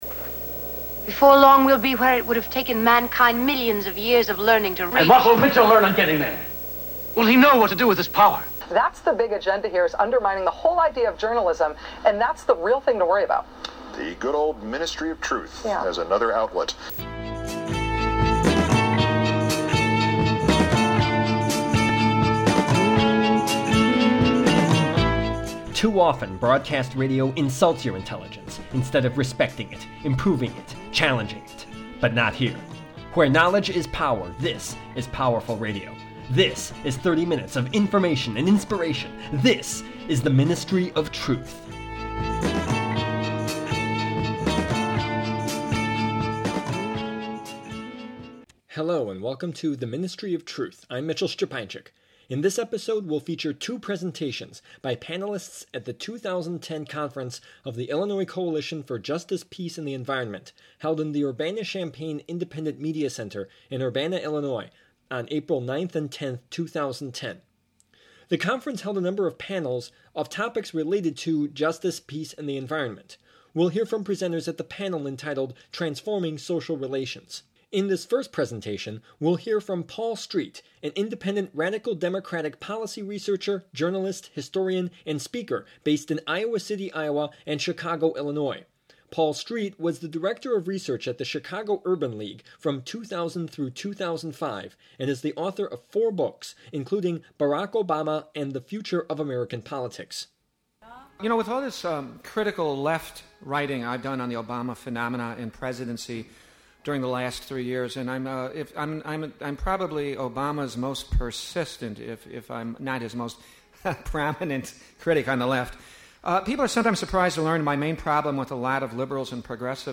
The Ministry of Truth: Presentations
at the 2010 ICJPE Conference